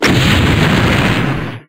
attack1_explode.wav